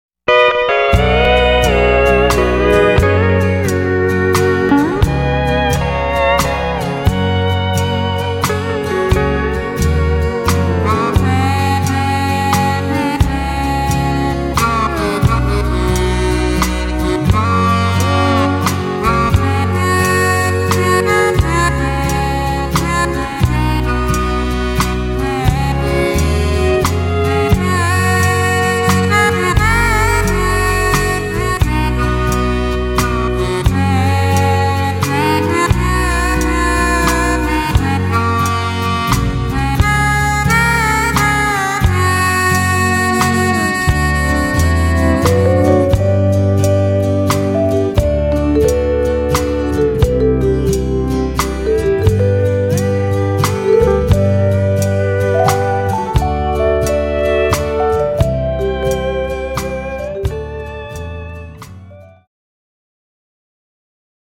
Cued Sample
Waltz, Phase 3